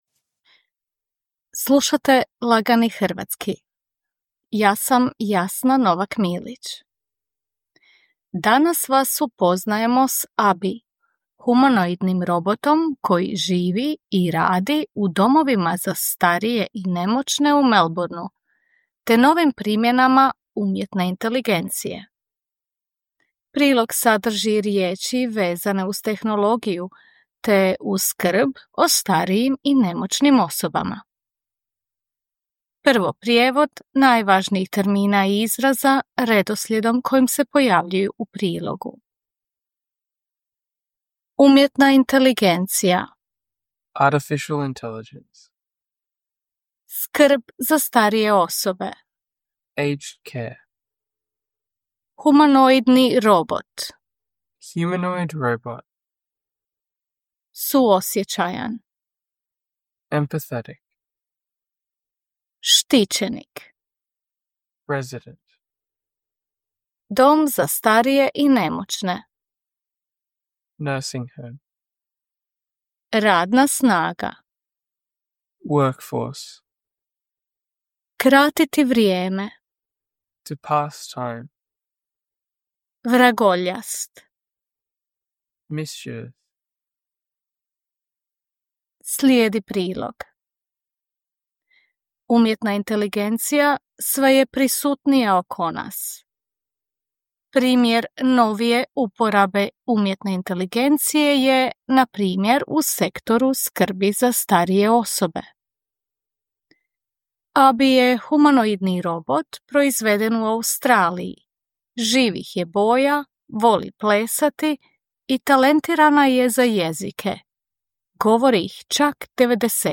Before we move on to the feature, you will hear some of the more complex vocabulary and expressions, followed by their English translations.
“Easy Croatian” is intended for those learning or wanting to brush up on their Croatian. News is written in simpler and shorter sentences and read at a slower pace.…